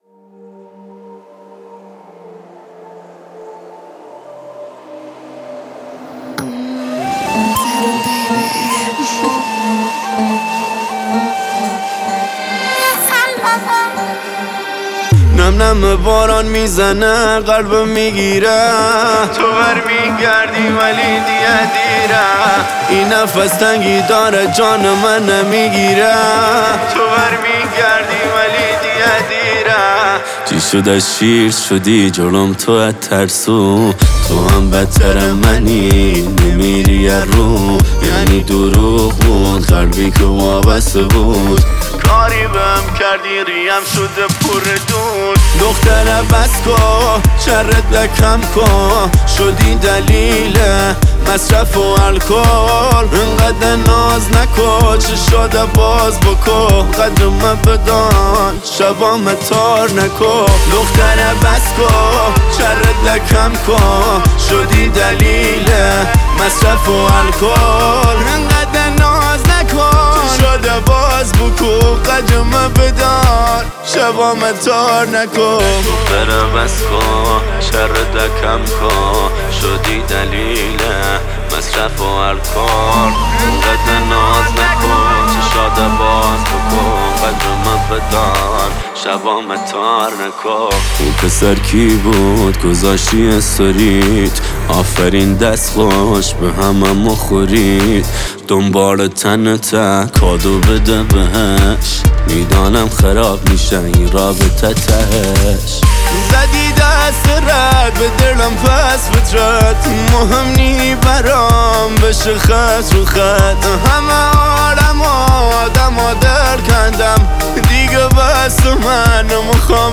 احساسی